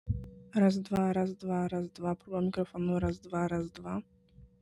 Dźwięk jest czysty, stosunkowo ciepły i przede wszystkim nie zniekształca głosu.
Czysty dźwięk bez zmiany ustawień